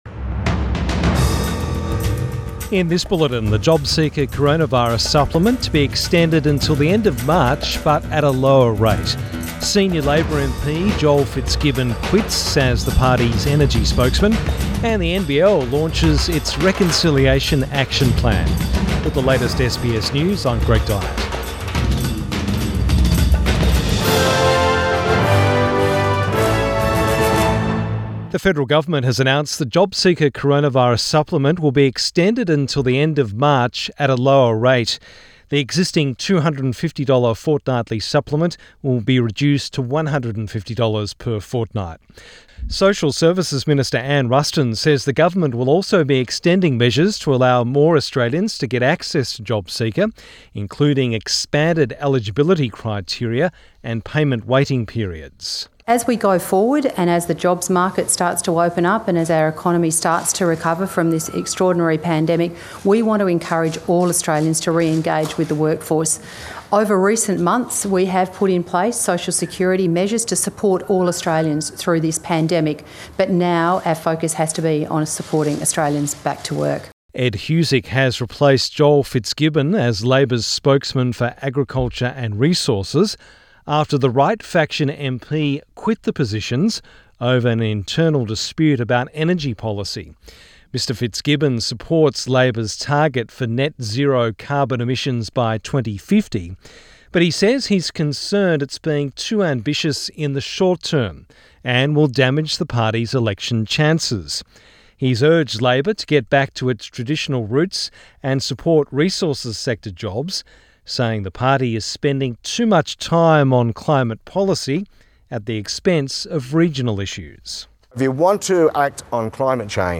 PM bulletin 10 November 2020